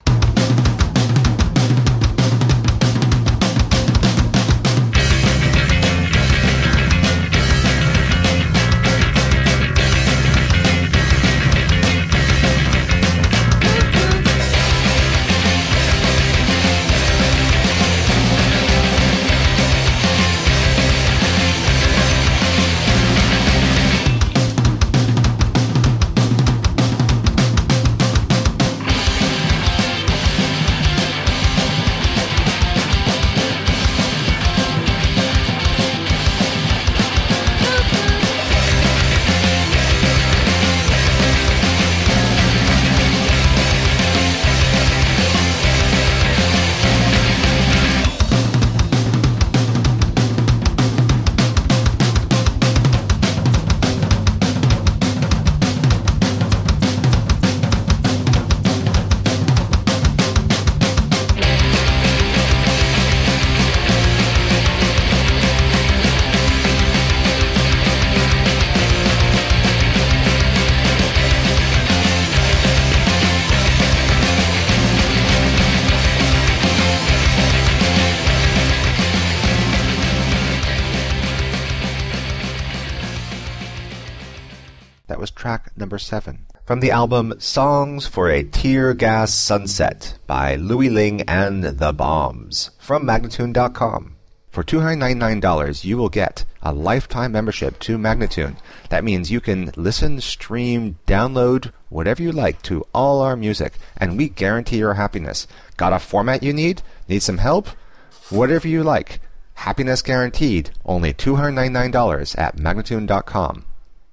Punk rock to blow your head off.
Tagged as: Hard Rock, Punk, Intense Metal